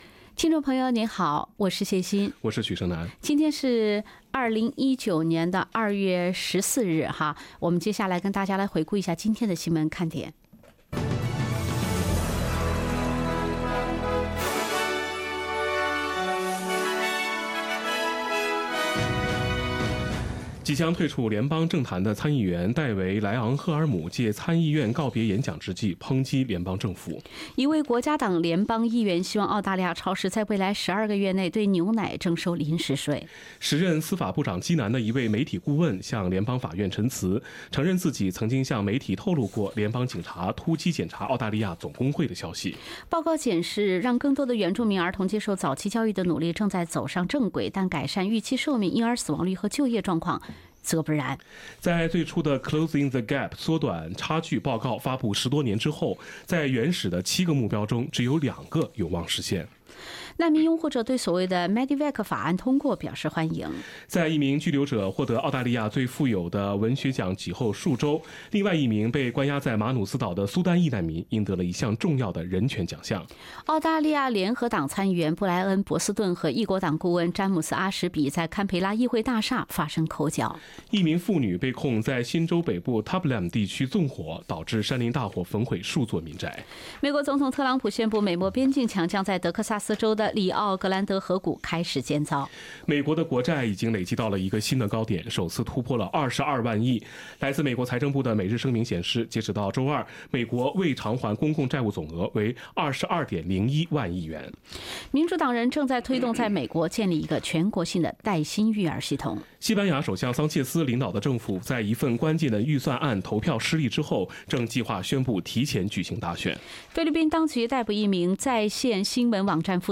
SBS早新闻（2月14日）